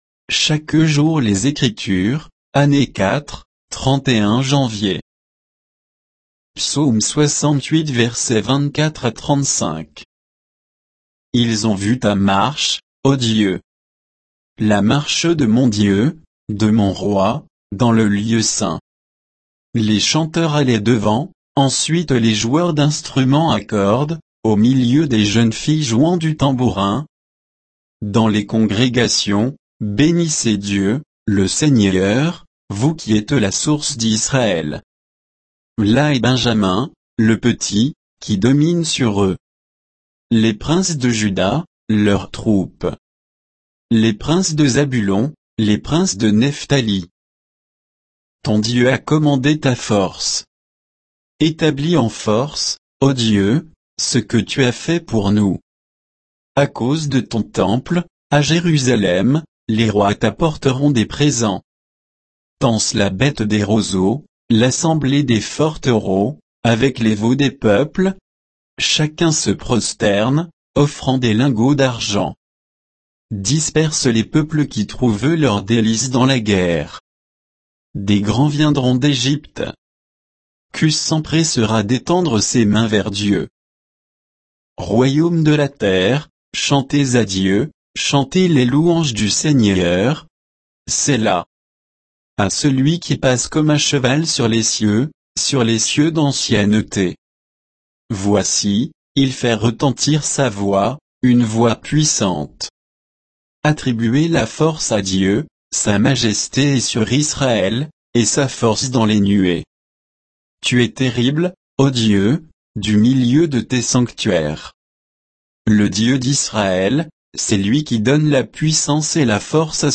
Méditation quoditienne de Chaque jour les Écritures sur Psaume 68